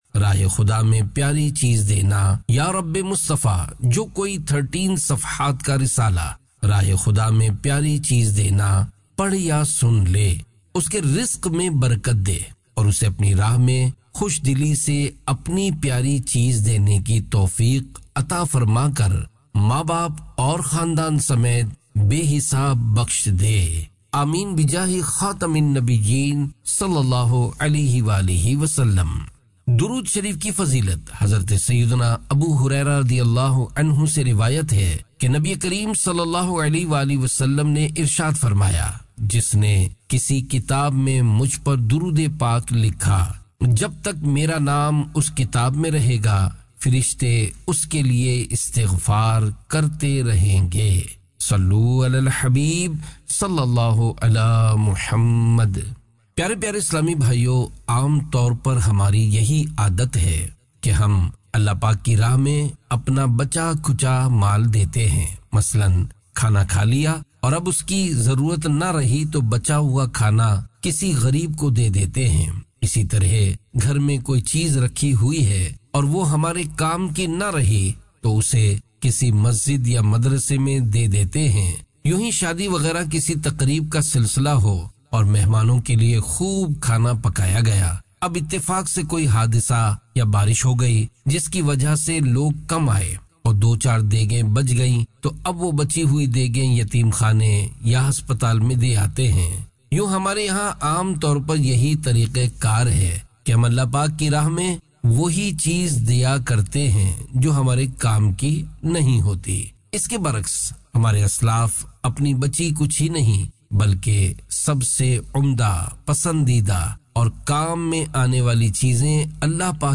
Audiobook - Rah e Khuda Me Pyari Cheez Dena (Urdu)